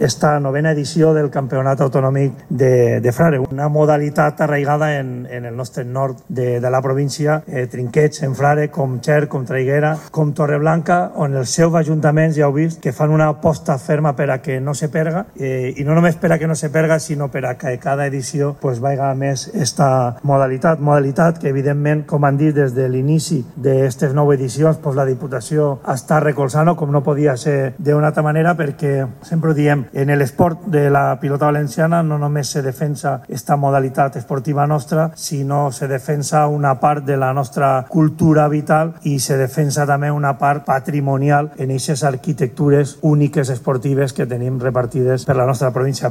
Diputado-Ivan-Sanchez-presentacion-de-Frare.mp3